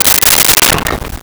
Glass Bottle Break 01
Glass Bottle Break 01.wav